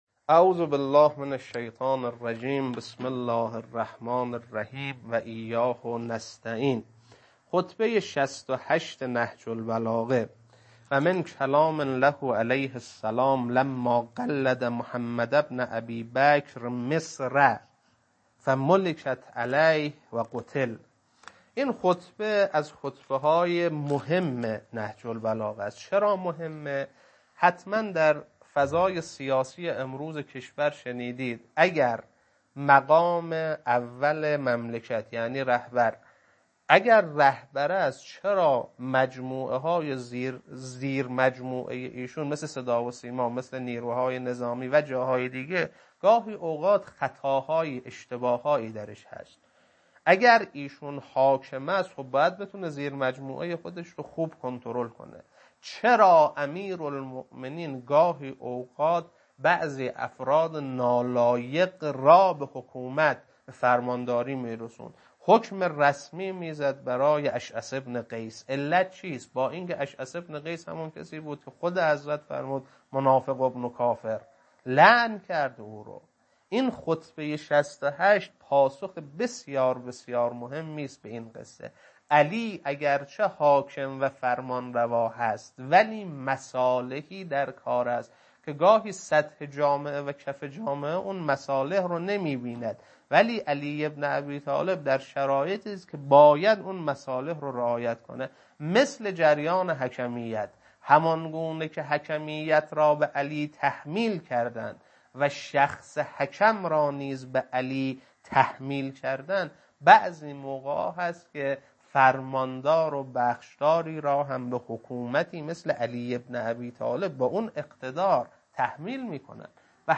خطبه 68.mp3